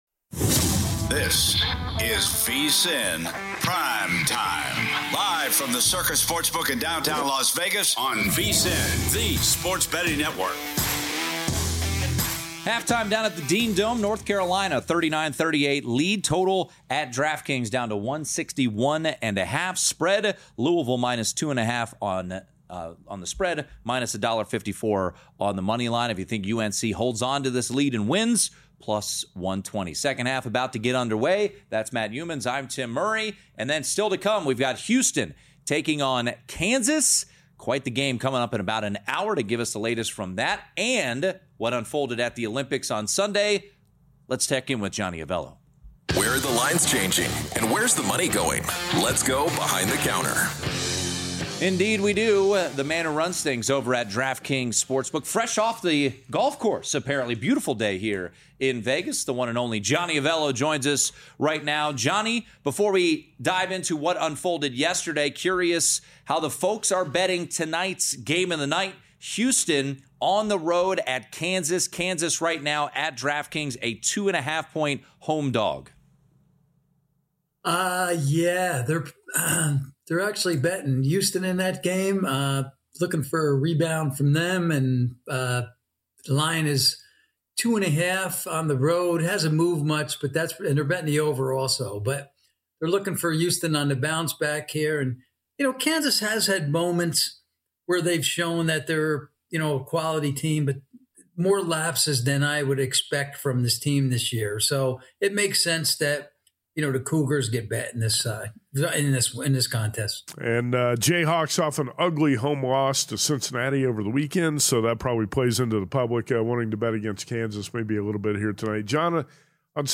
They also cover the recent Olympic hockey events, highlighting the USA's victory over Canada. Tune in for expert commentary and betting strategies from the Circa Sportsbook in Las Vegas.